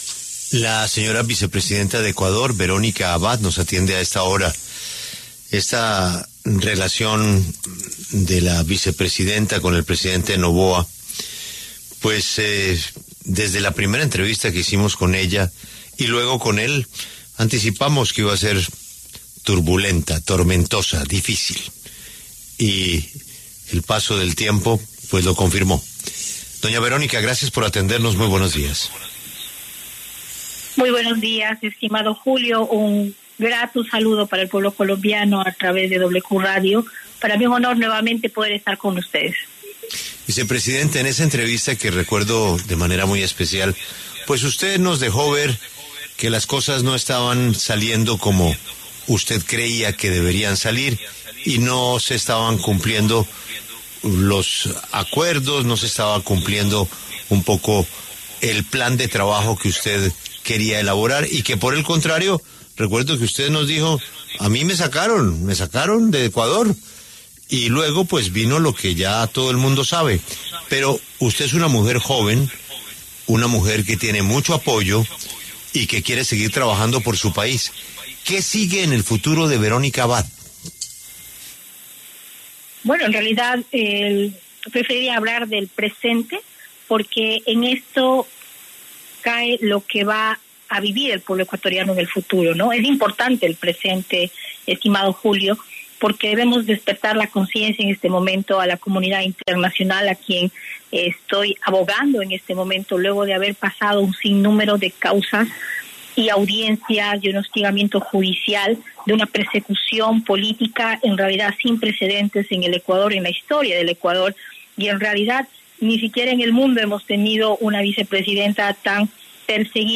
Verónica Abad, vicepresidenta de Ecuador, conversó con La W sobre cómo está el panorama en su país de cara a la segunda vuelta presidencial entre el presidente Daniel Noboa y Luisa González.